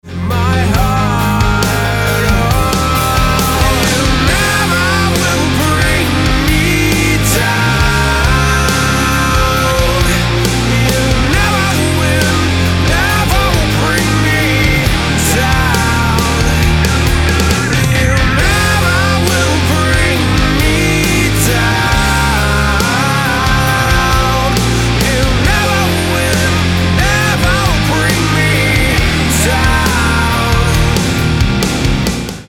• Качество: 320, Stereo
мужской вокал
Hard rock